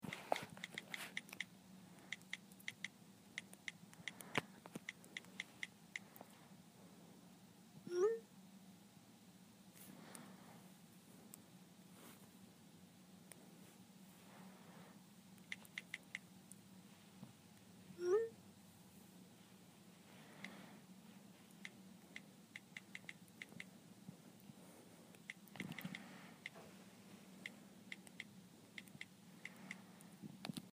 Field Recording #5
Location: Common Room on my floor
Sounds Heard: typing on a iphone, a send noise, shuffling noises, breathing, static.